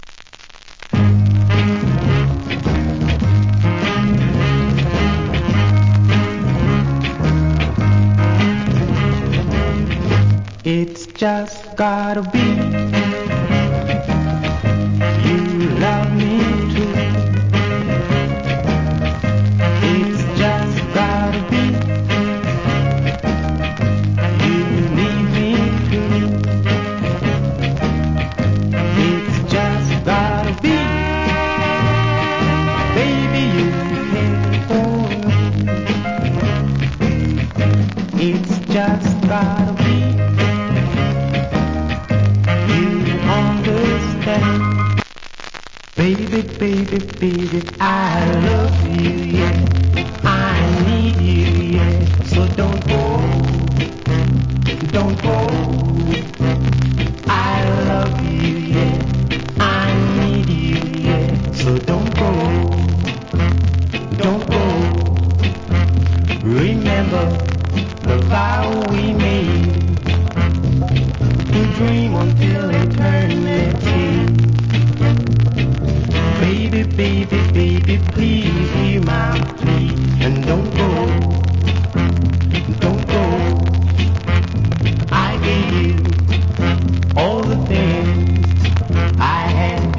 60's Nice Vocal.